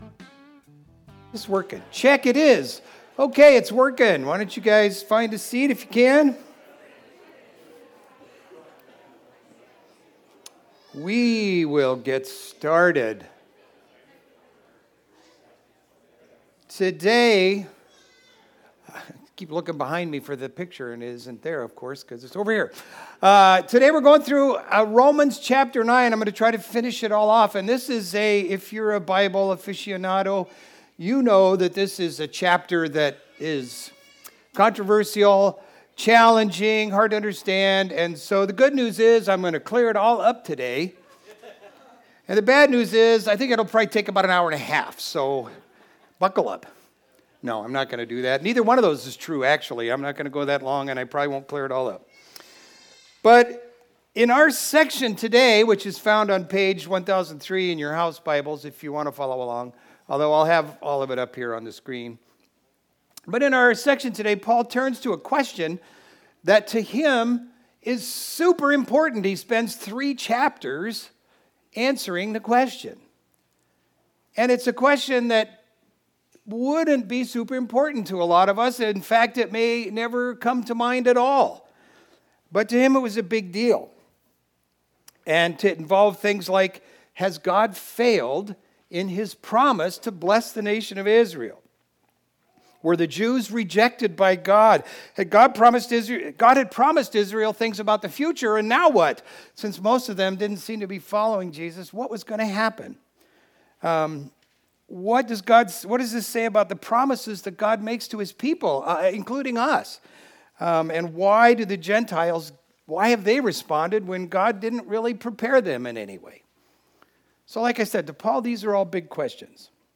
Video Audio Download Audio Home Resources Sermons Can God’s Plan Fail?